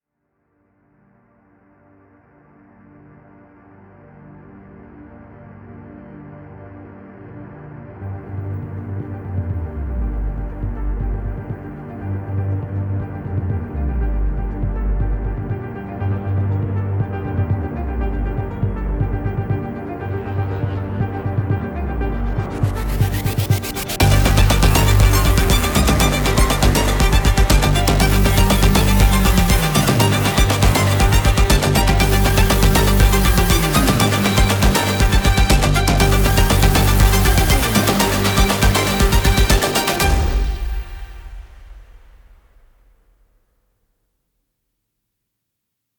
———————————————— Production Music Examples ————————————————